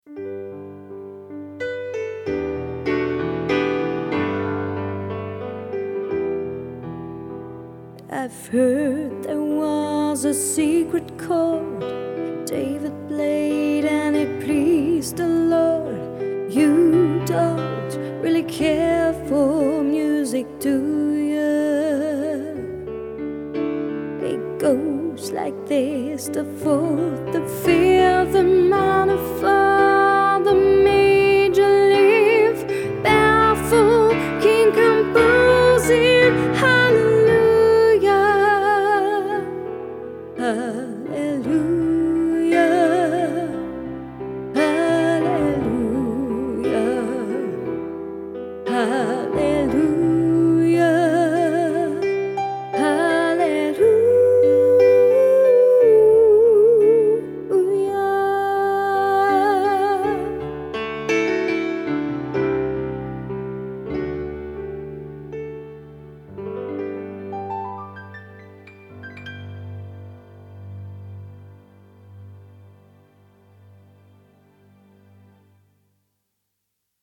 Die Hochzeitssängerin op Kölsch